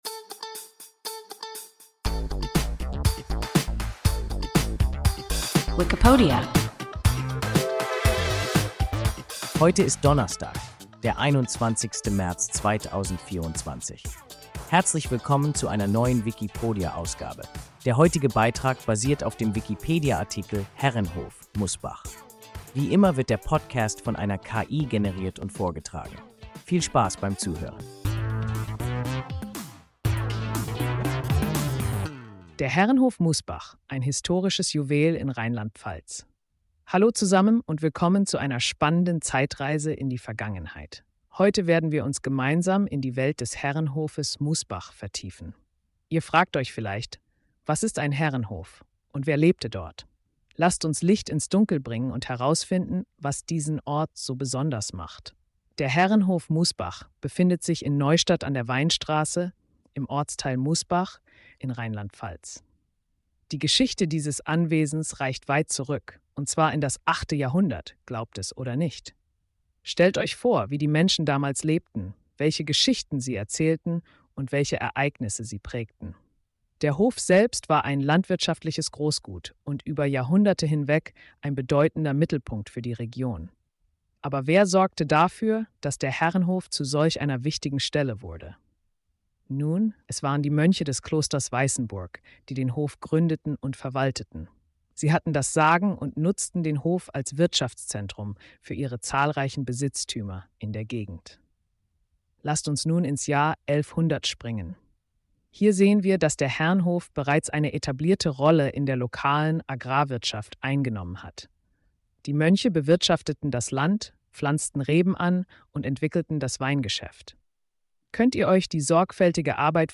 Herrenhof (Mußbach) – WIKIPODIA – ein KI Podcast